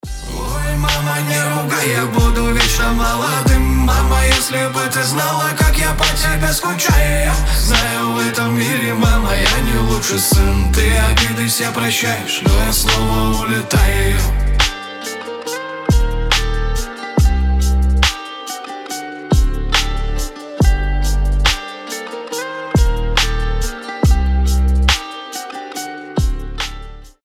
Рэп рингтоны , Гитара
Лирика , Душевные